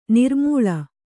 ♪ nirmūḷa